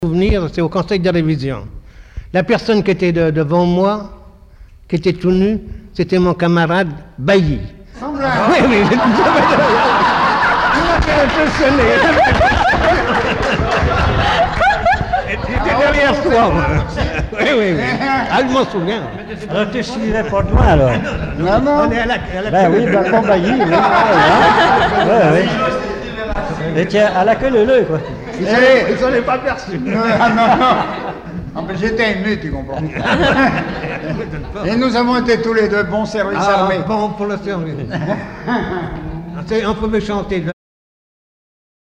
Regroupement au foyer logement
Catégorie Témoignage